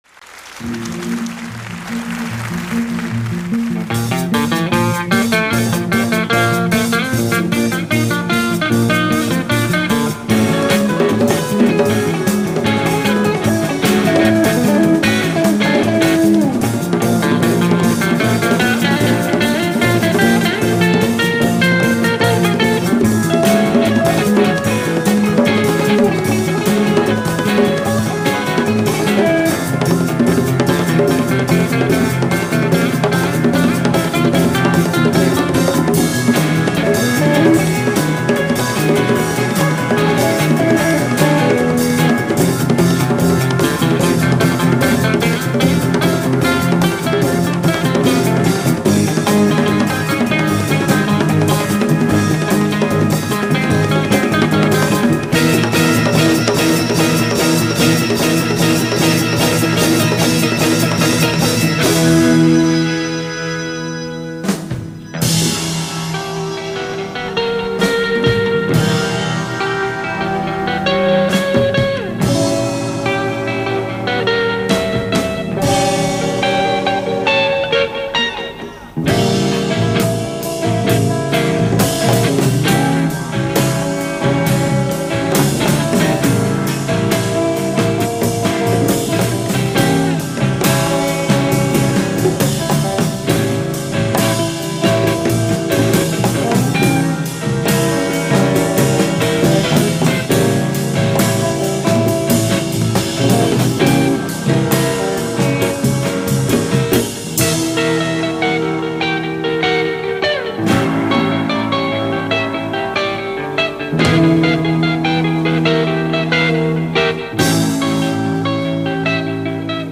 Recorded in 1971, Paris